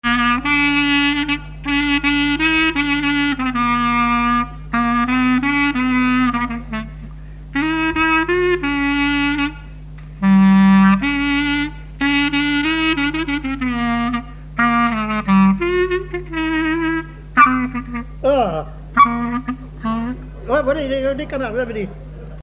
gestuel : à marcher
collectif de musiciens pour une animation à Sigournais
Pièce musicale inédite